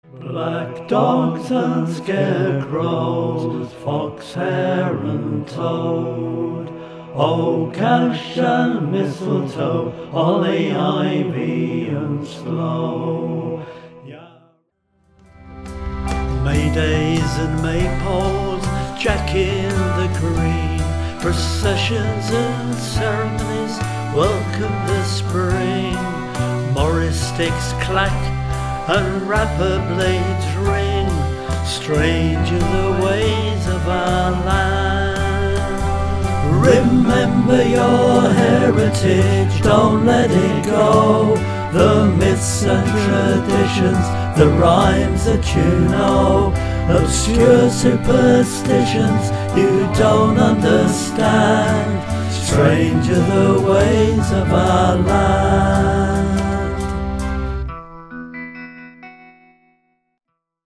This song is at the very early rehearsal stage.